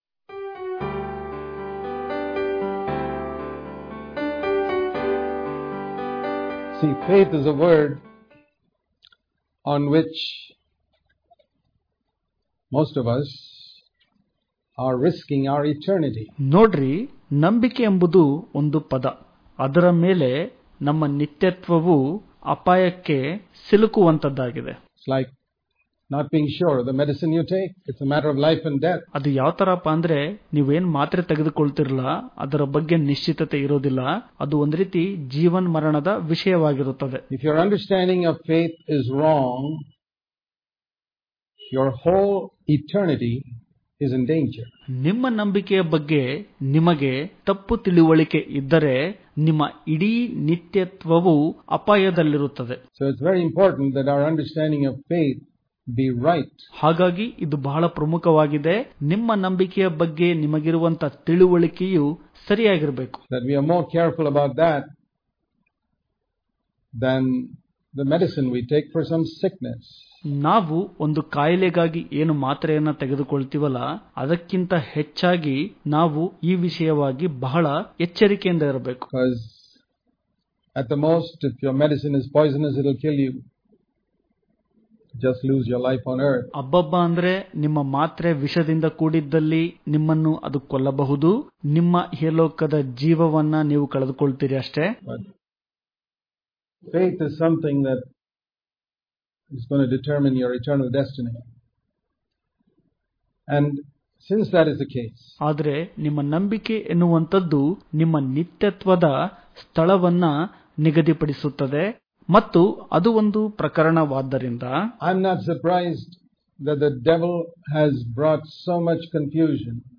October 20 | Kannada Daily Devotion | Faith Under The Old Testament And The New Testament Daily Devotions